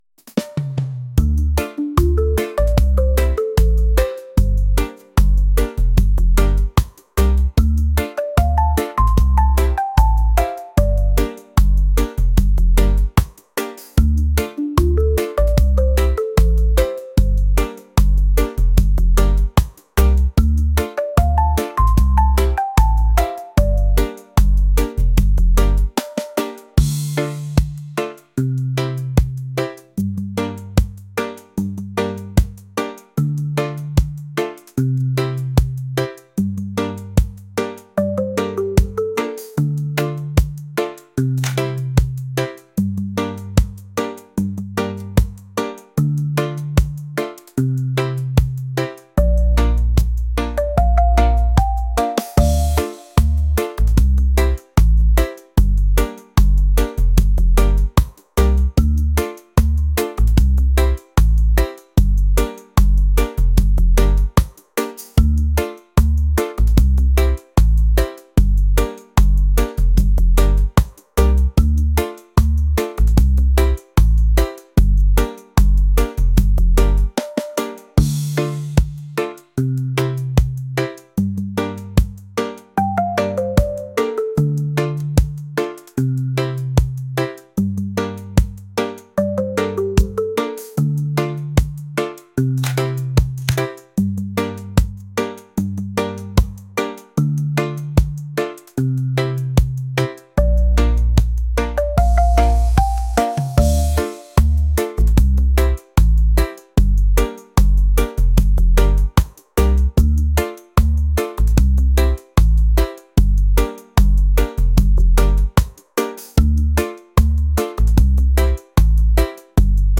reggae | lounge | acoustic